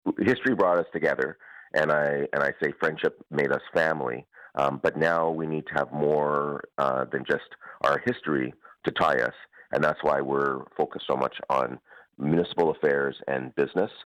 Belleville Mayor Mitch Panciuk says the relationship has evolved over time.